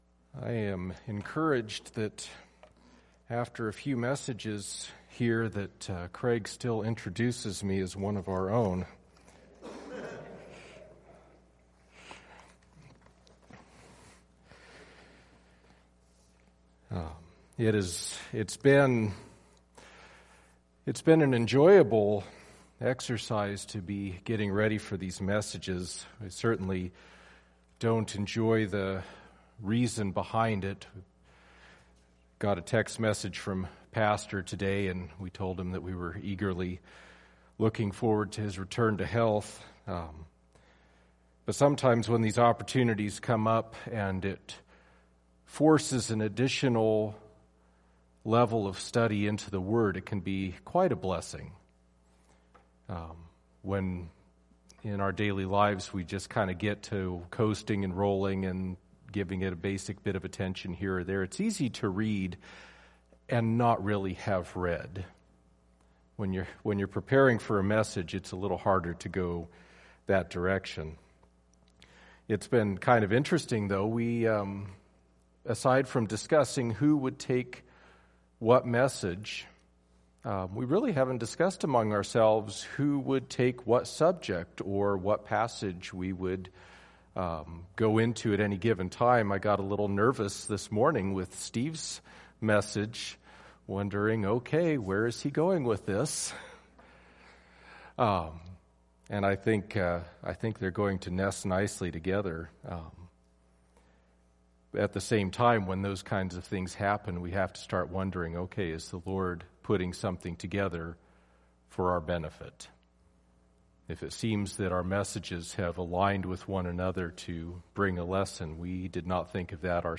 Lord’s Supper Message, First Baptist Church
Lord’s Supper Message